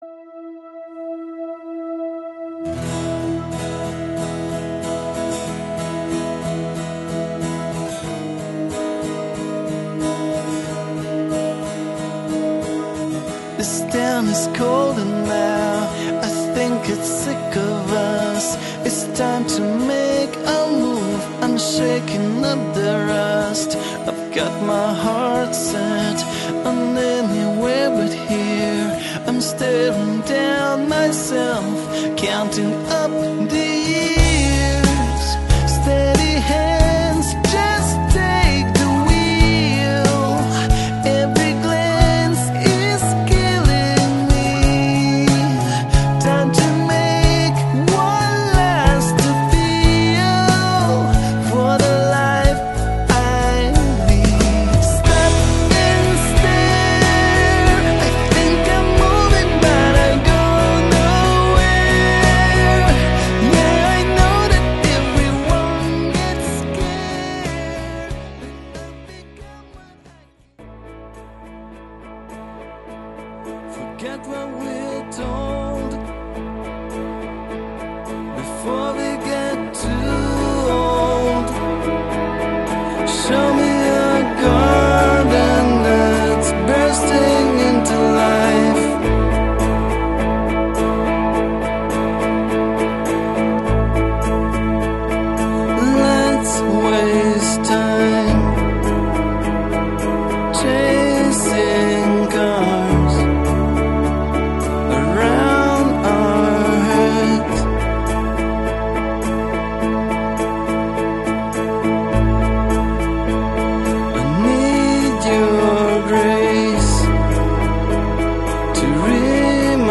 Melbourne based guitarist/singer
skillful guitar playing and his great stage presence